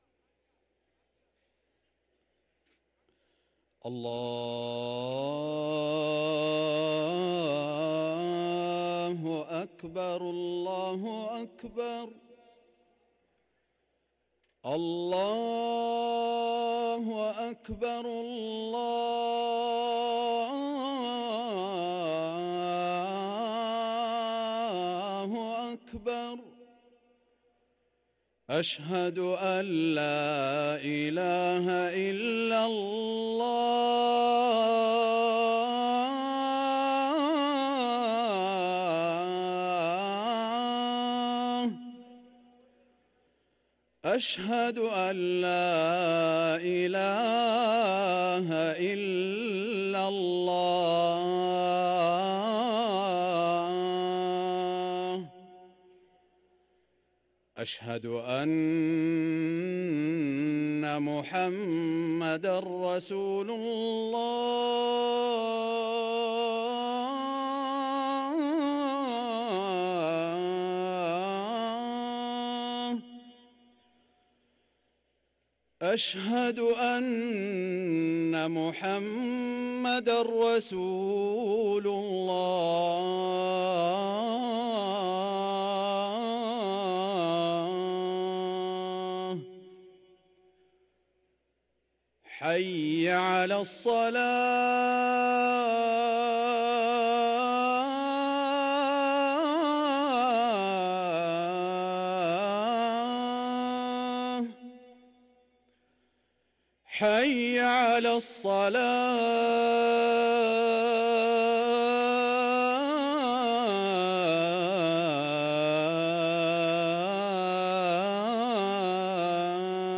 أذان المغرب للمؤذن محمد العمري الاثنين 1 رجب 1444هـ > ١٤٤٤ 🕋 > ركن الأذان 🕋 > المزيد - تلاوات الحرمين